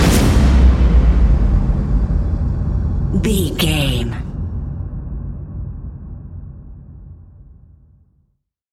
Epic / Action
Fast paced
In-crescendo
Ionian/Major
industrial
dark ambient
EBM
drone
synths
Krautrock